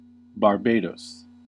1. ^ UK: /bɑːrˈbdɒs/ bah-BAY-doss; US: /bɑːrˈbds/
bar-BAY-dohss; locally /bɑːrˈbdəs/ bar-BAY-dəss
En-us-Barbados.ogg.mp3